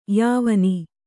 ♪ yāvani